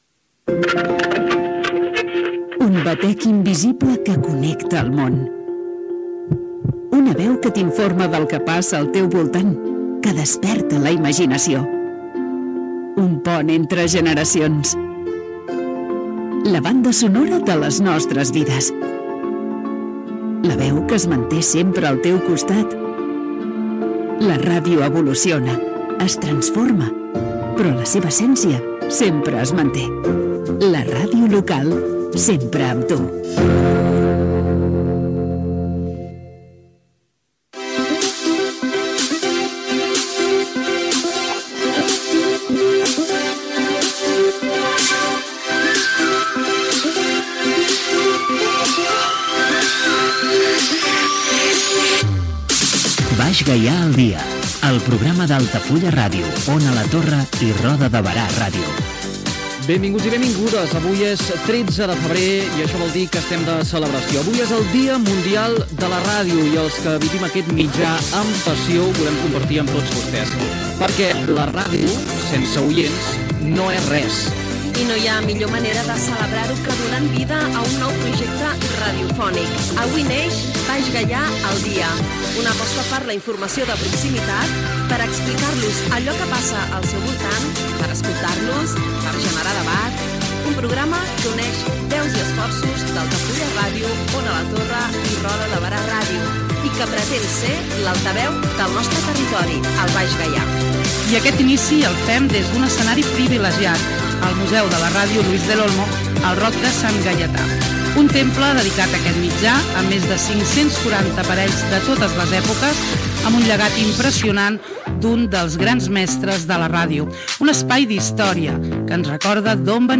Primera edició del programa, feta des del Museu de la Ràdio Luis del Olmo de Roda de Berà.
Entreteniment